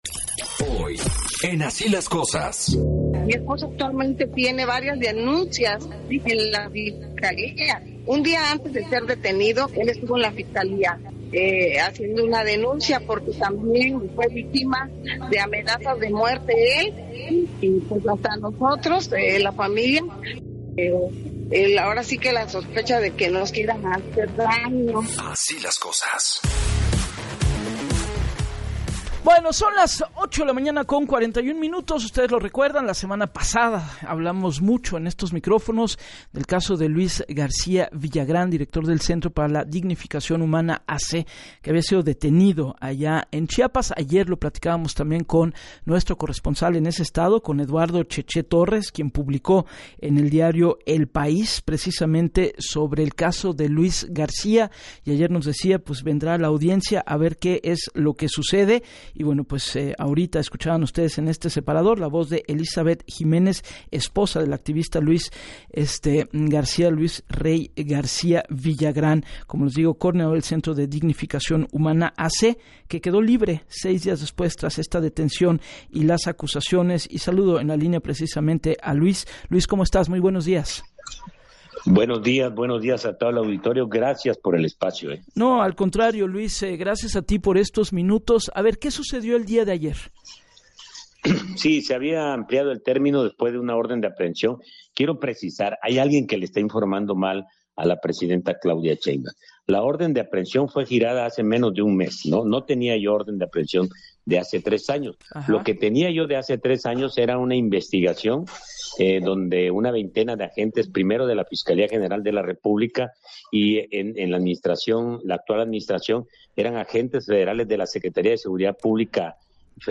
En entrevista para Así las Cosas con Gabriela Warkentin, aseguró que su aprehensión fue un intento de criminalizar la defensa de migrantes y que existe un patrón de persecución contra quienes disienten del gobierno.